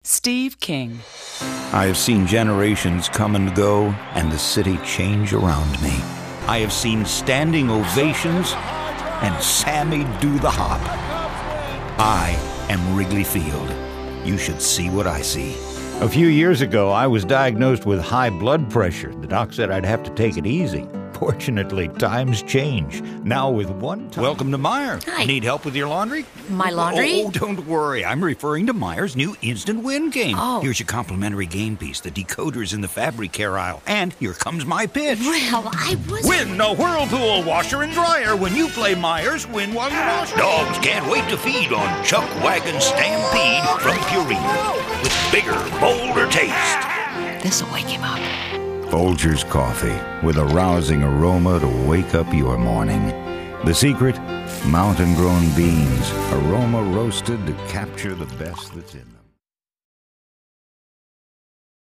narration : men